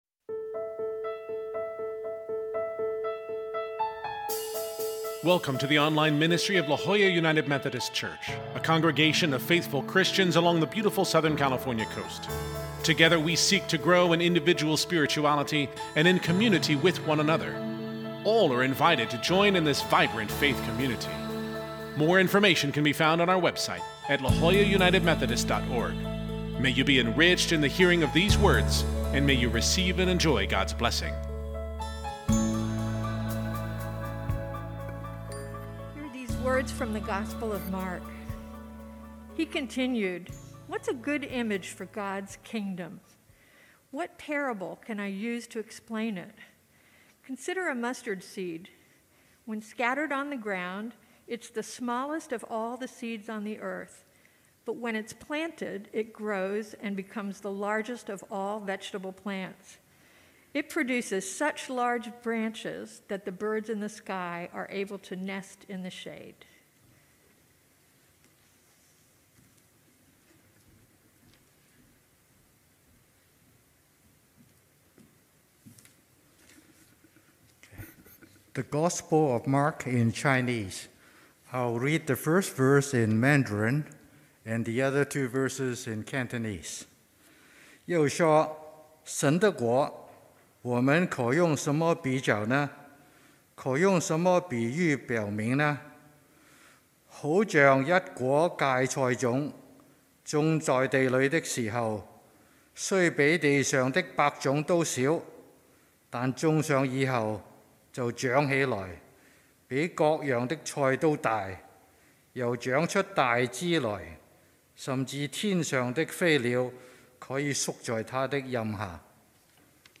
This Sunday we continue our sermon series on the parables of Jesus, guided by the book Short Stories by Jesus: The Enigmatic Parables of a Controversial Rabbi by Amy-Jill Levine.